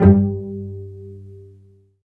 Index of /90_sSampleCDs/Roland - String Master Series/STR_Vcs Marc-Piz/STR_Vcs Pz.4 Oct
STR CLLO P04.wav